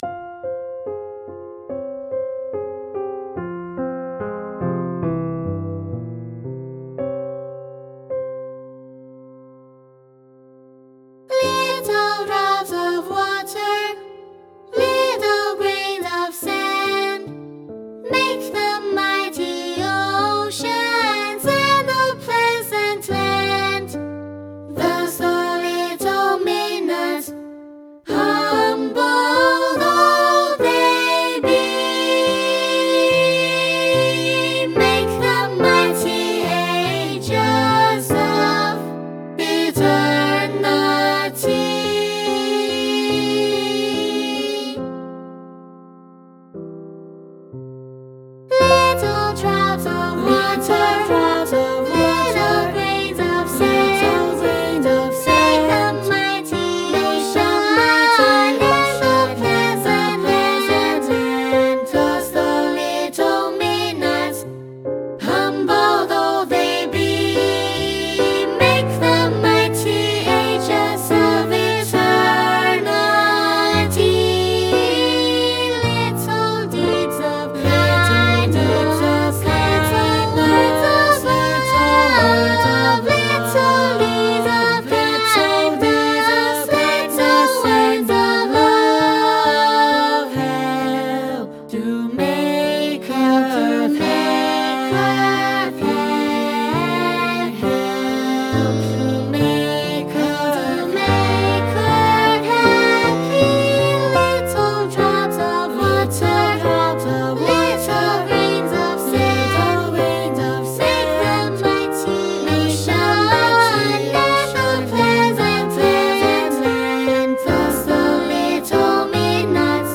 SA + Piano
SA, Piano